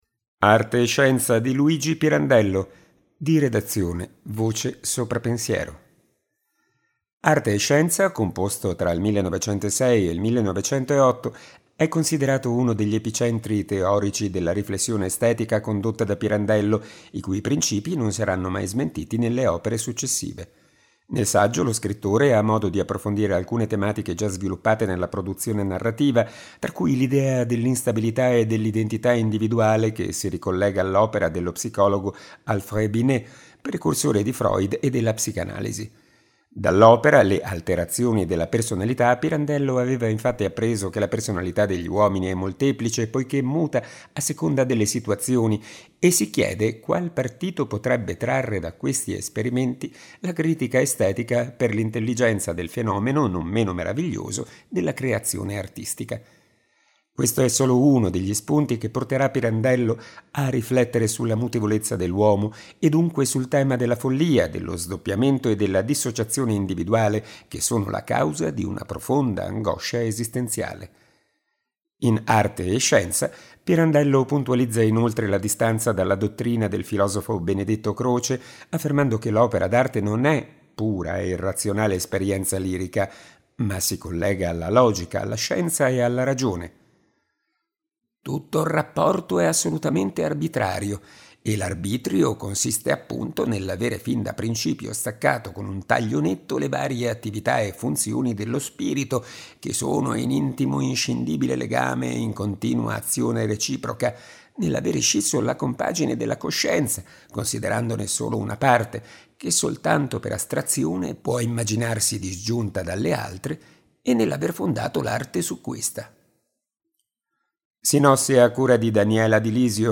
Dall’incipit del libro: